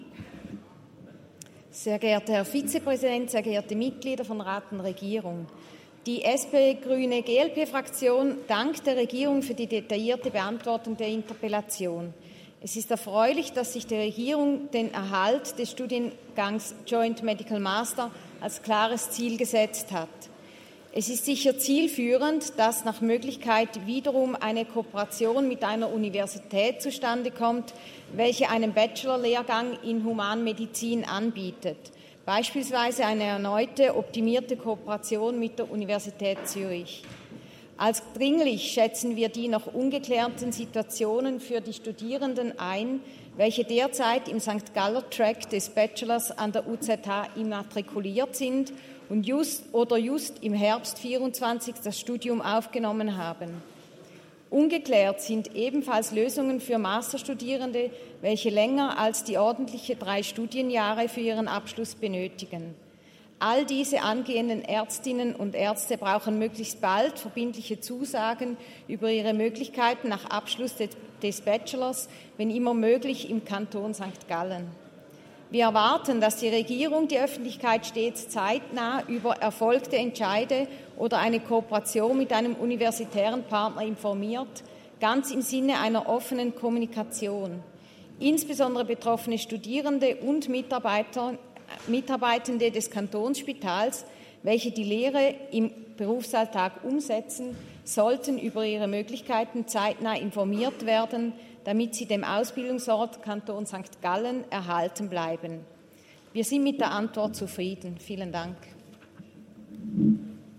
Hasler-Balgach (im Namen der SP-GRÜNE-GLP-Fraktion): Dem Antrag auf Dringlicherklärung ist zuzustimmen.